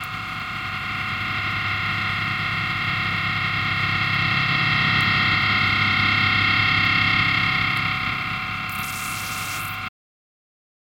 Rauschen/Brummen bei Epiphone SG-400 nach Pickup-Tausch
Denn es klingt definitiv nicht nach tiefem Brummen, sondern eher nach einem hohen Surren (Anhang).